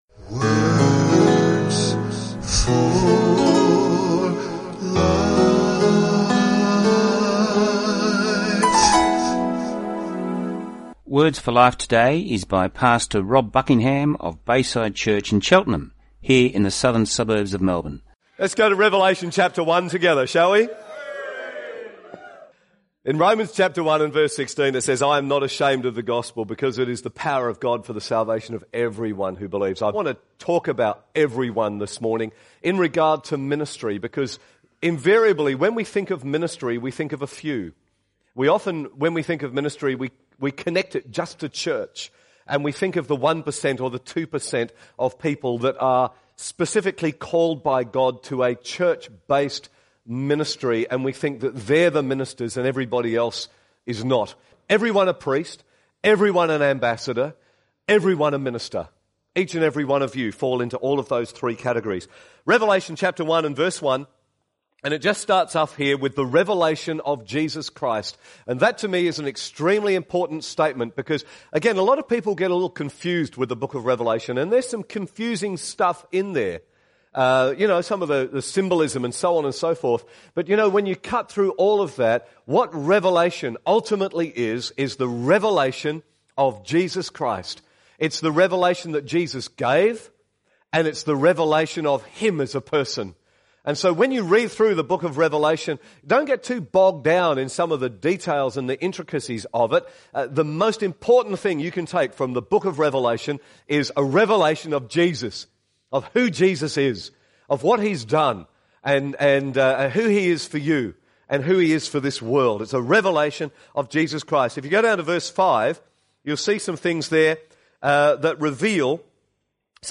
Talk time is 24 minutes.